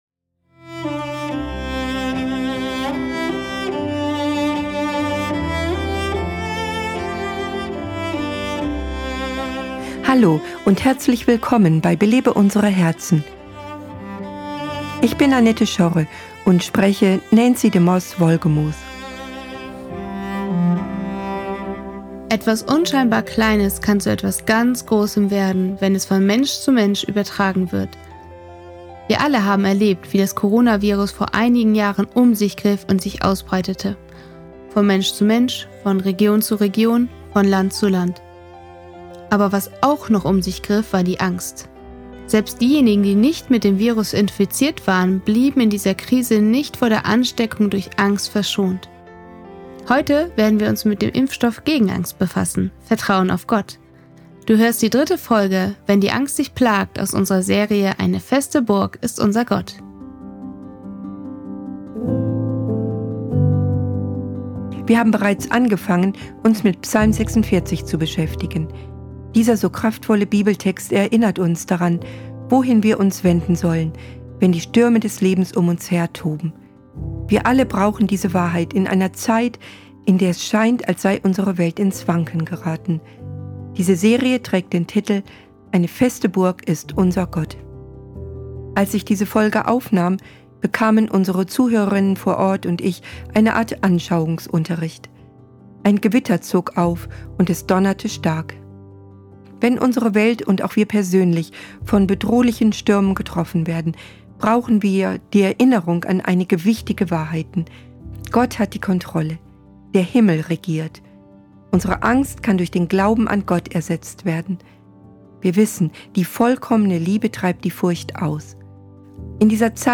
Als ich diese Folge aufnahm, bekamen unsere Zuhörerinnen vor Ort und ich eine Art Anschauungsunterricht. Ein Gewitter zog auf, und es donnerte stark.